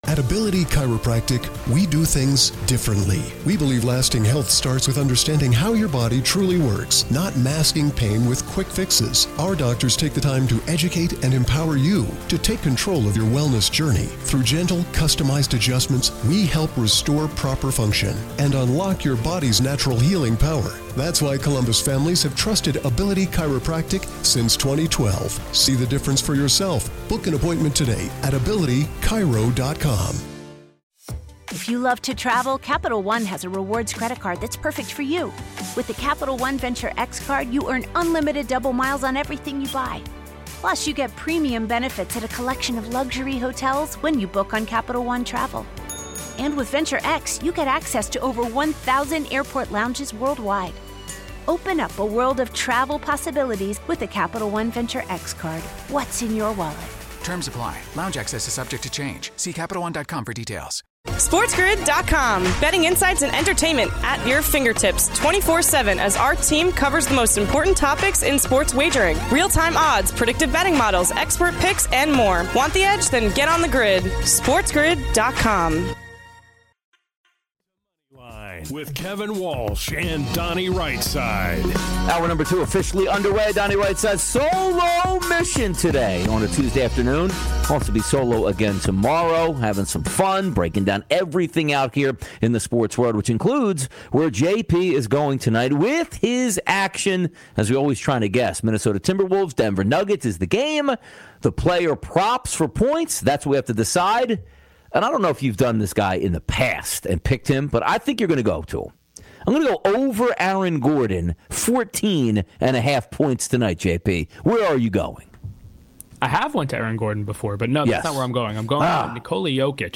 Then - a preview of the triple header in the NBA tonight. He wraps it up with MLB picks and best bets. Your calls, his picks and takes, and more!